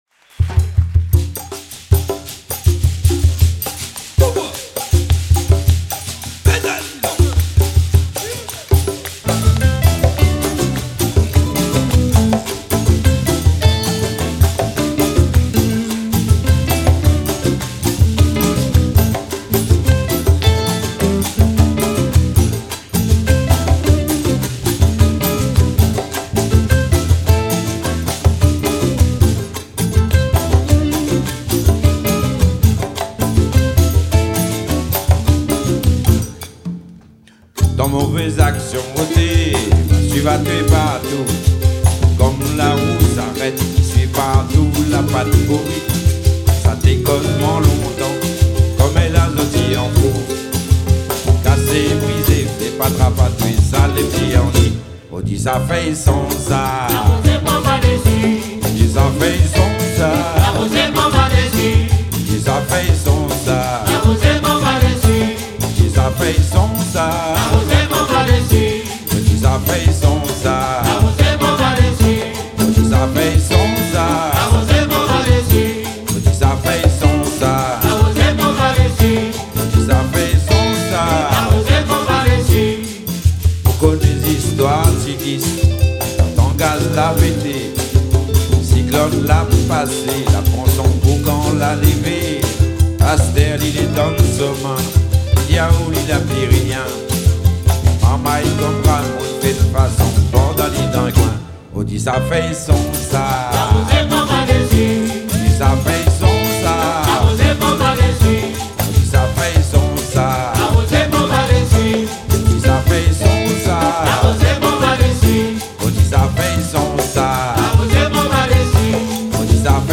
Sega, Maloya, Seggae, Sagaï..